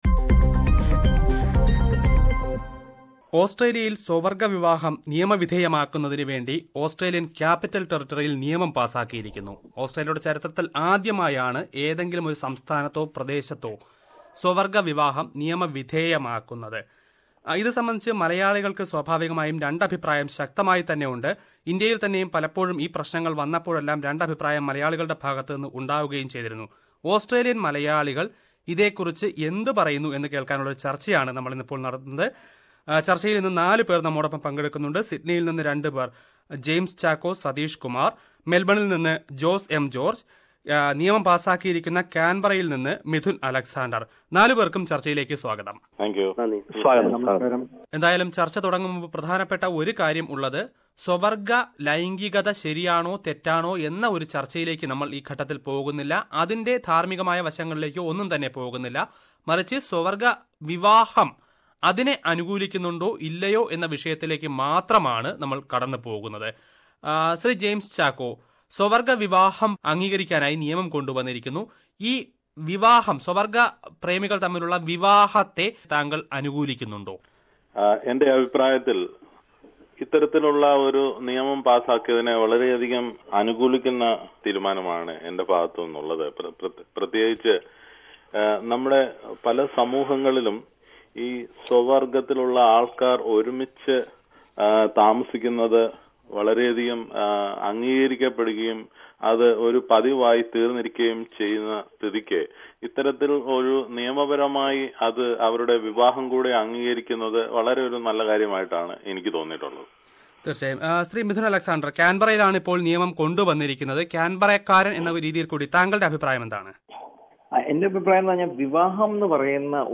Listen to a panel discussion on the legalization of same sex marriage ….